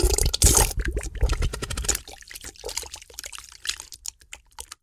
Fart Diarrhea Fart Sound Effect Free Download
Fart Diarrhea Fart